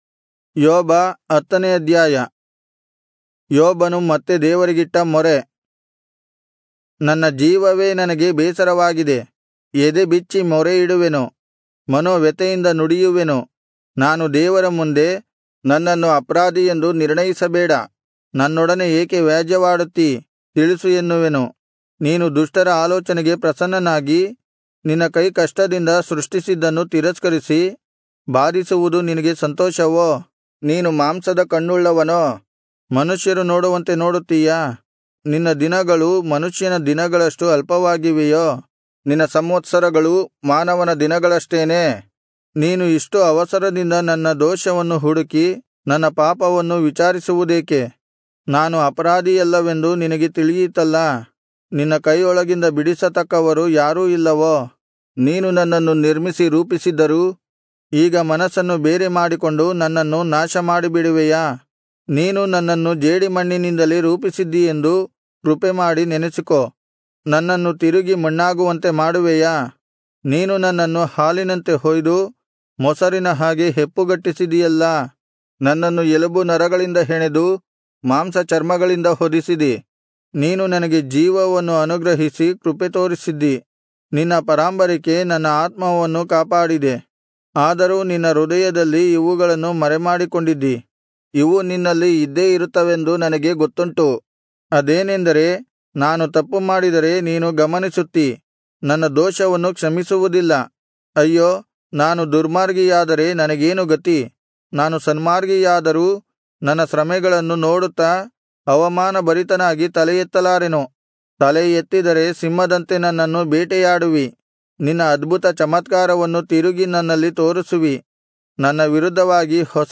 Kannada Audio Bible - Job 42 in Irvkn bible version